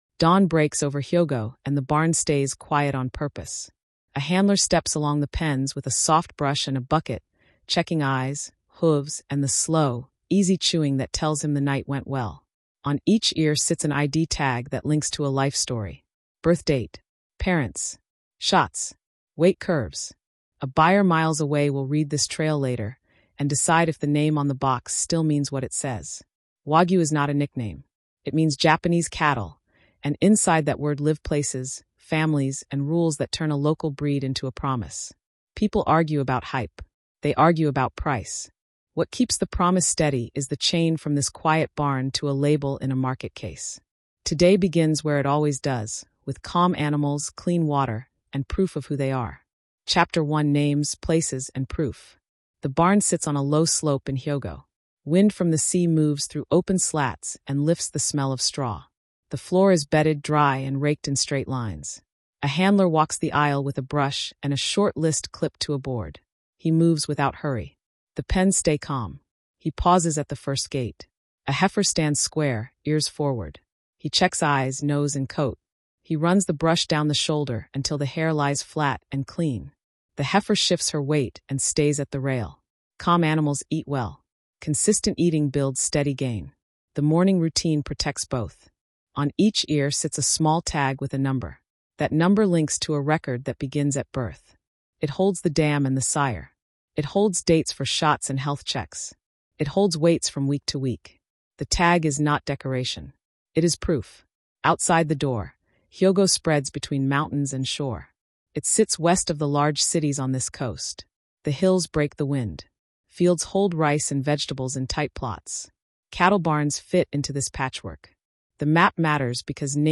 This food history documentary follows Wagyu from Hyōgo barns to grading rails, showing how proof, patience, and low-stress care build the melt people talk about. We explain breed identity, the long finish, Japan’s A–C yield and 1–5 quality grades with BMS marbling, and how protected names like Kobe are verified. You’ll see how global programs adapt the idea without losing clarity, and what chefs check before a cut hits the grill or hot pot.